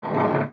描述：木椅
标签： 移动 木材 椅子
声道立体声